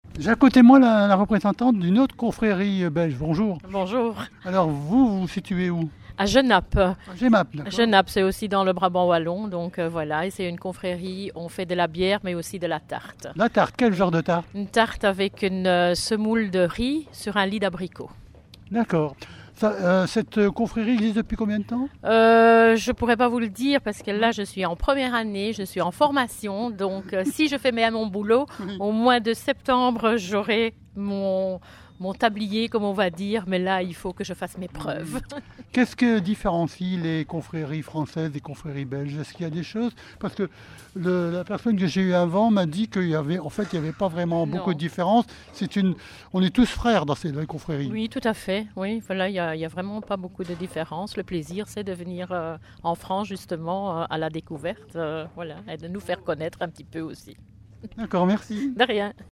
VAG MUSIC- CONFRERIES -INTERVIEW NO 4